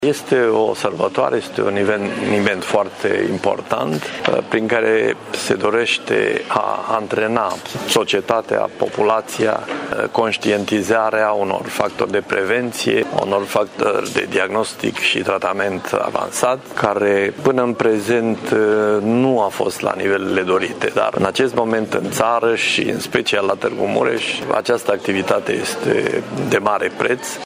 Pe lângă activitățile științifice, evenimentele de la Institutul Inimii se vor axa și asupra prevenției, primul pas către o inimă sănătoasă, spune directorul executiv al Agenției Naționale de Transplant, prof.dr. Radu Deac: